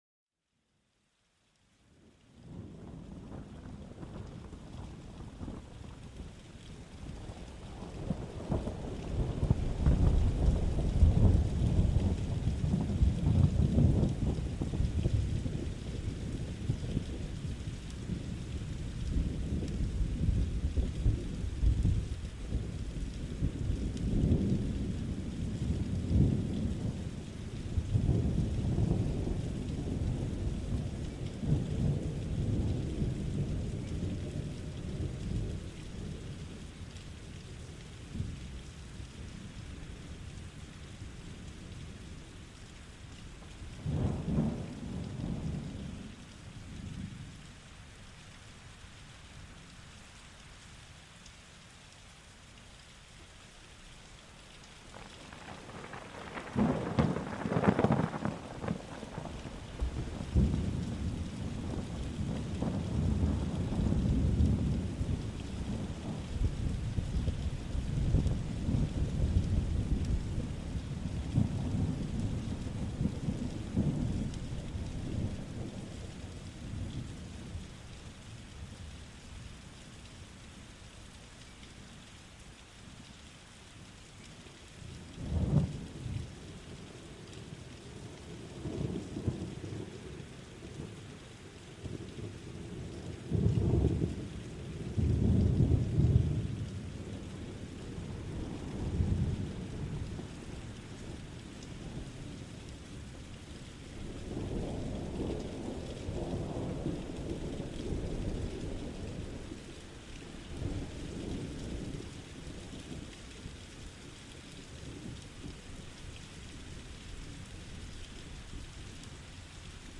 ⛈ Lluvia y Trueno: Calma Natural para una Relajación Profunda
Descubre el poder calmante de las tormentas en nuestro nuevo episodio. Escucha los sonidos cautivadores de la lluvia cayendo y el trueno a lo lejos, perfectos para una relajación última.